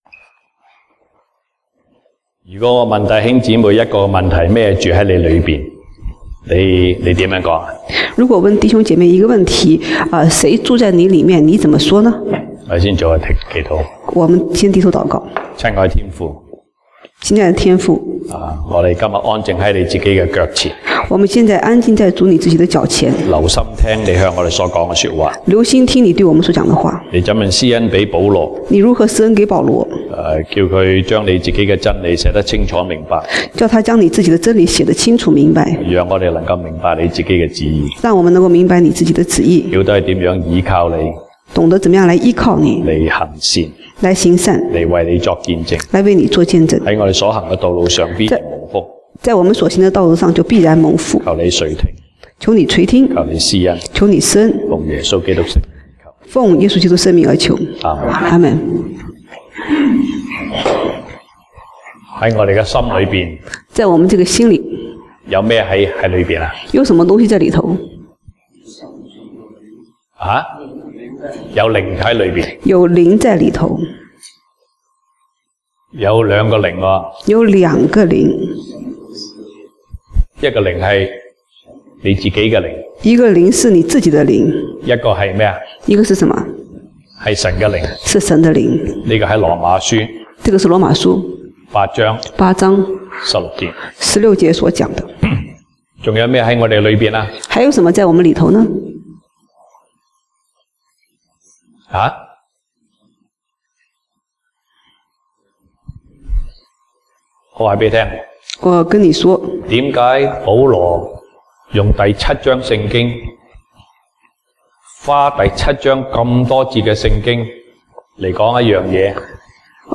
西堂證道(粵語/國語) Sunday Service Chinese: 誰住在我裡頭？